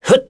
Esker-Vox_Attack1.wav